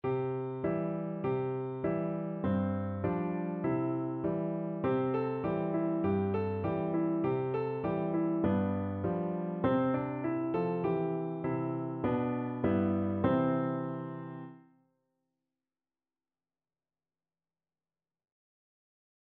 World Asia Japan Tsuki
Free Sheet music for Piano Four Hands (Piano Duet)
4/4 (View more 4/4 Music)
C major (Sounding Pitch) (View more C major Music for Piano Duet )
Simply